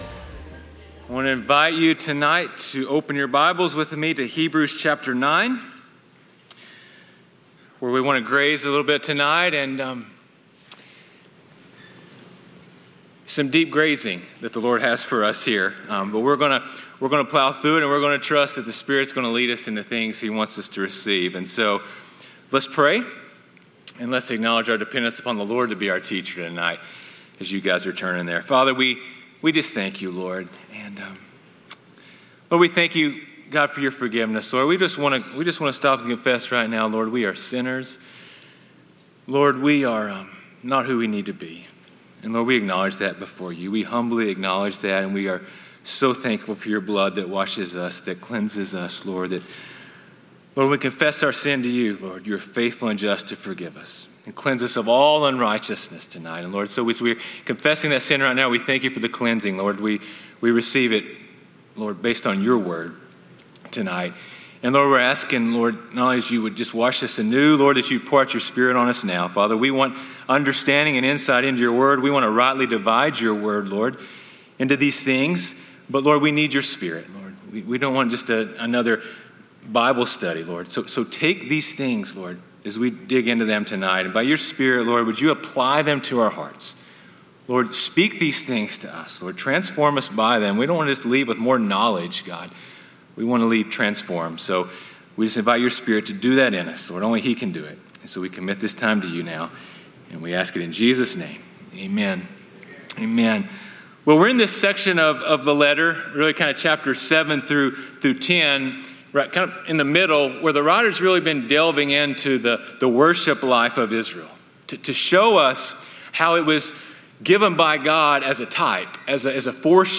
sermons Hebrews 9:1-28 | A Superior Sanctuary